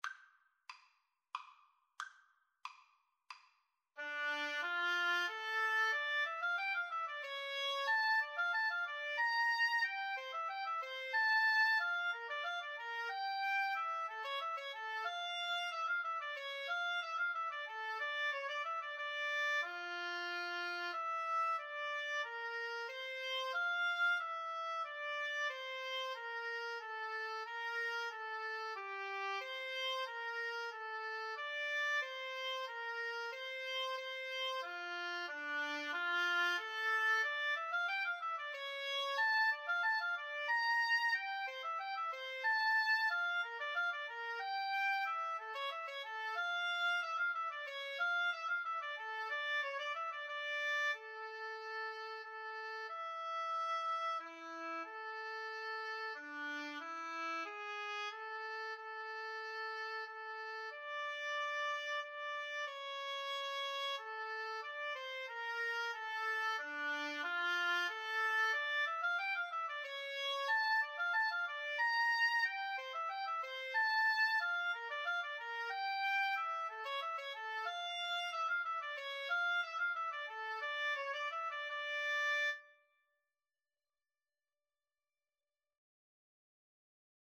Play (or use space bar on your keyboard) Pause Music Playalong - Player 1 Accompaniment reset tempo print settings full screen
D minor (Sounding Pitch) E minor (Clarinet in Bb) (View more D minor Music for Clarinet-Oboe Duet )
Allegro Moderato = c. 92 (View more music marked Allegro)
Classical (View more Classical Clarinet-Oboe Duet Music)